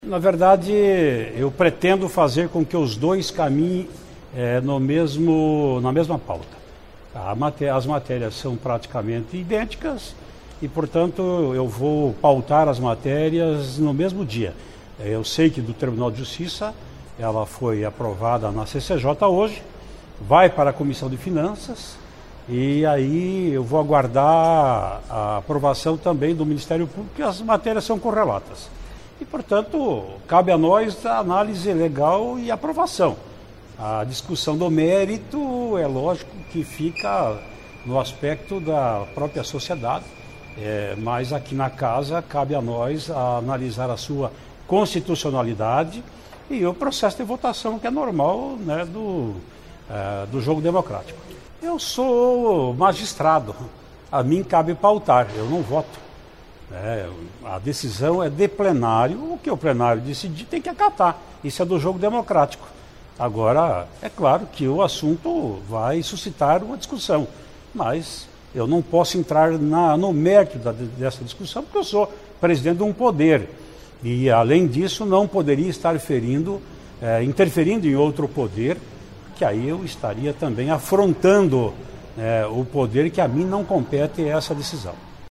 Ouça a entrevista dada pelo presidente Traiano , antes da sessão plenária desta terça-feira (27), onde ele explica o  trâmite de projetos do TJ e MP, que estão sendo analiados nas comissões da Casa. O do Tribunal de Justiça foi aprovado na Comissão de Constituição e Justiça (CCJ) na reunião desta terça-feira , Ele cria  gratificação por exercício cumulativo de atribuições judiciais e/ou administrativas e de acervo.